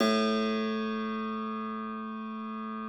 53l-pno05-A0.aif